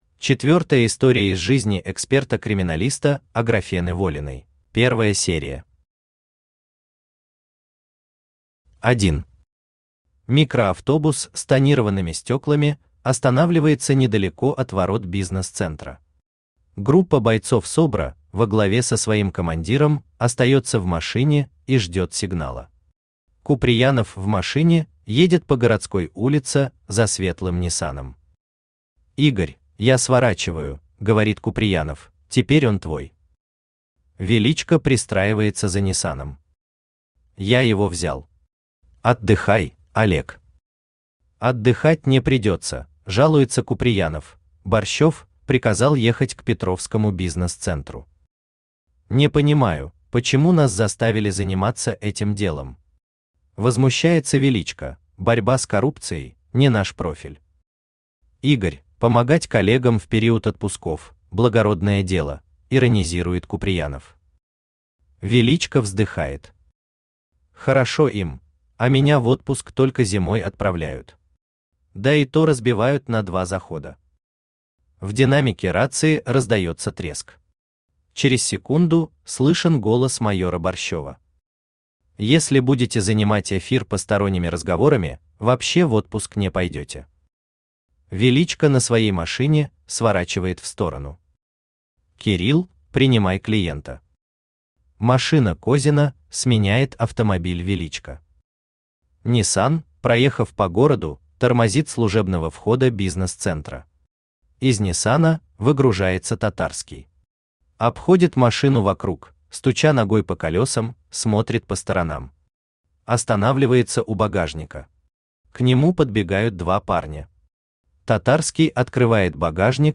Аудиокнига Чупакабра. Пропажа | Библиотека аудиокниг
Пропажа Автор Сергей Алексеевич Глазков Читает аудиокнигу Авточтец ЛитРес.